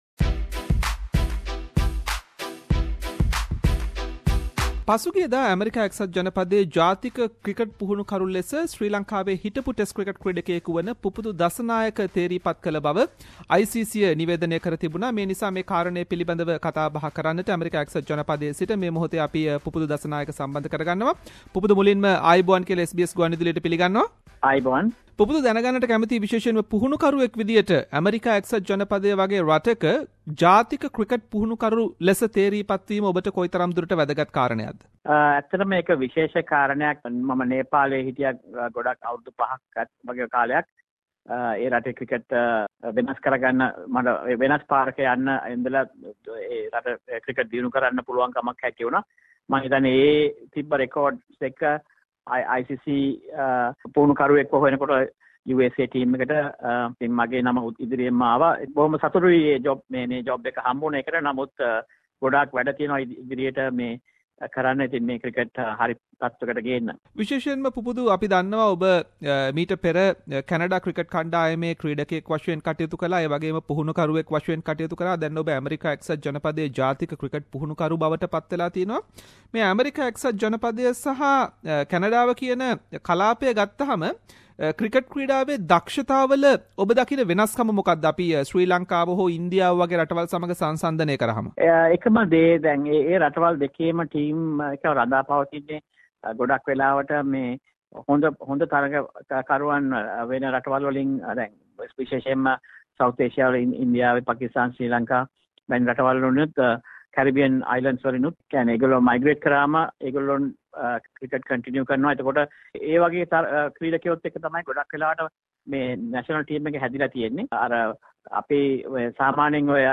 Former Sri Lankan cricketer Pubudu Dassanyake is recently appointed as the USA national cricket coach and SBS Sinhalese interviewed Pubudu regarding his new role and his future plans as a coach.